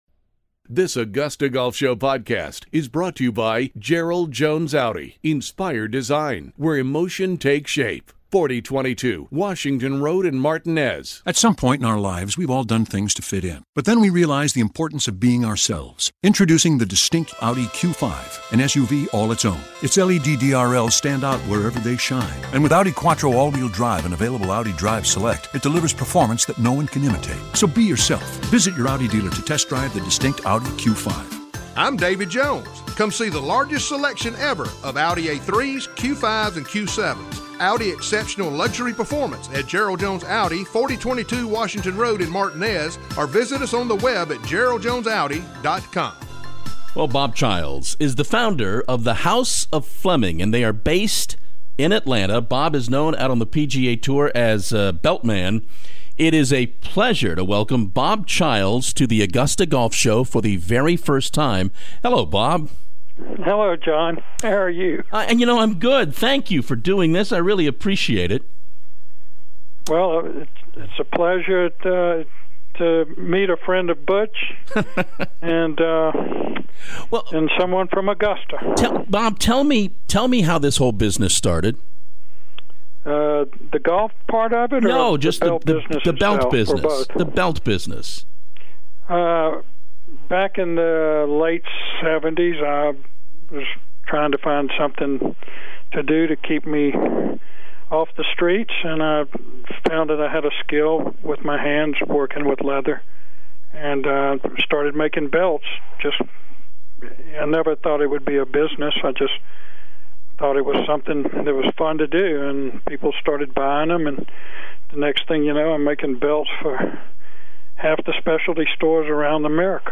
The Augusta Golf Show Interview